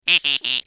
دانلود آهنگ حشره 1 از افکت صوتی انسان و موجودات زنده
دانلود صدای حشره 1 از ساعد نیوز با لینک مستقیم و کیفیت بالا
جلوه های صوتی